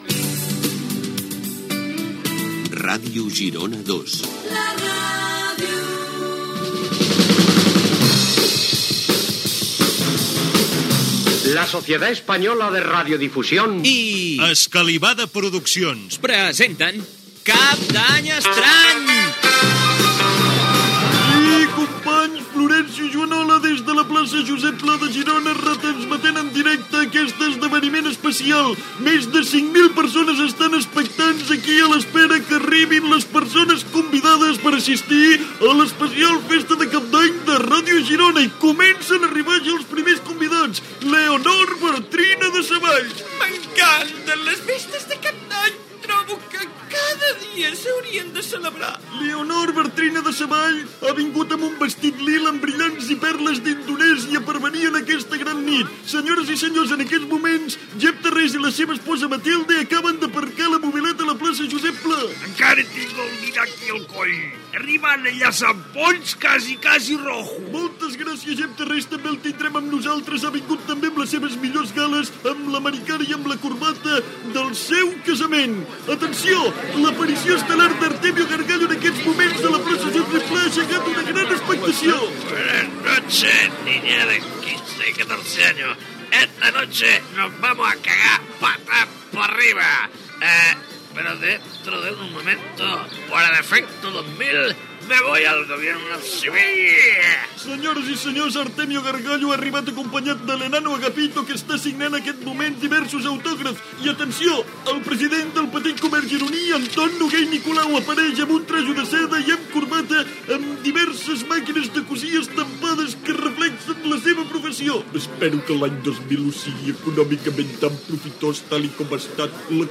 Careta del programa, presentació, arribada dels primers invitats a la festa de cap d'any, diàleg a l'estudi entre diversos personatges, tema musical, competició per ser el primer gironí de l'any a l'Hospital Josep Trueta, la sotsdelegació del govern està al cas de l'efecte 2000.
Entreteniment